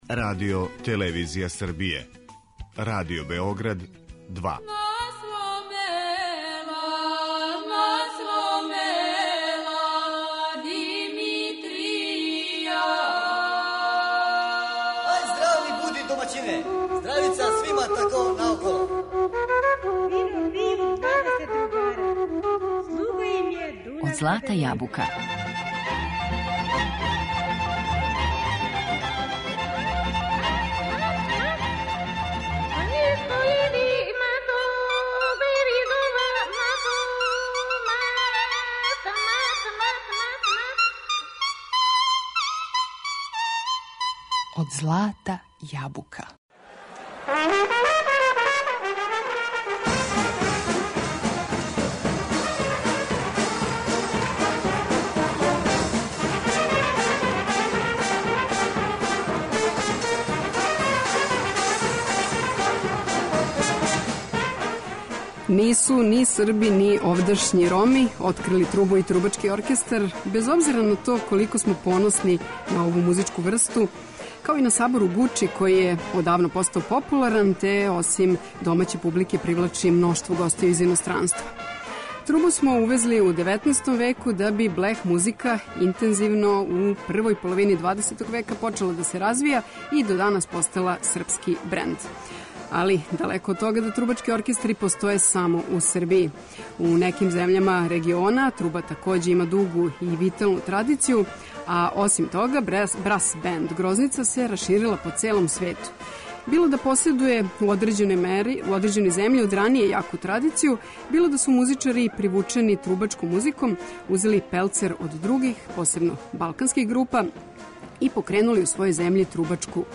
Блех оркестри изван Србије